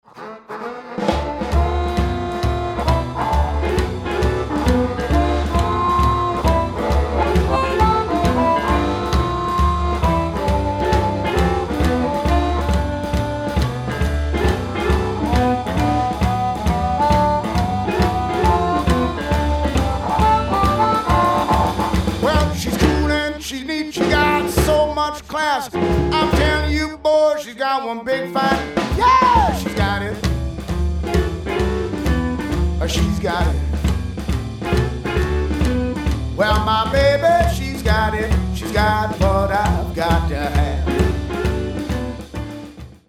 harmonica
Blues